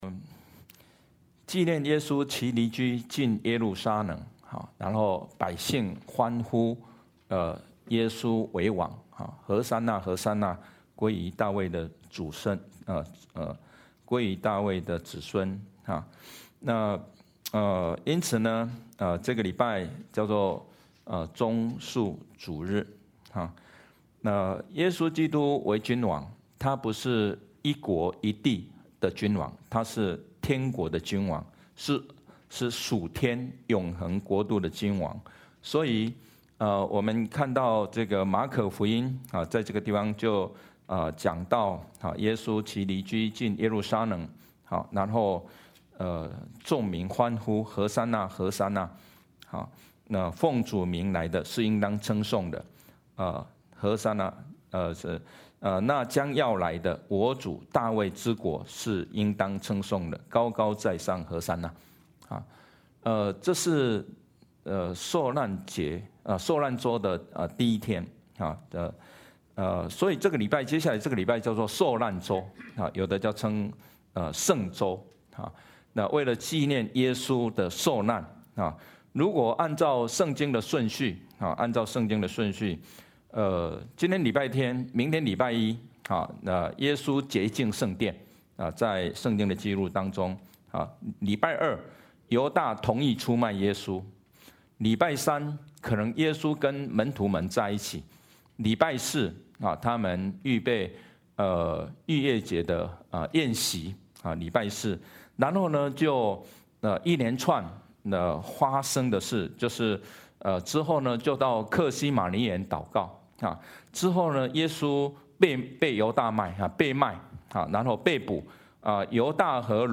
Passage: Jeremiah 3:18–33 Service Type: 主日证道 Download Files Notes « 不要怕，赶快去！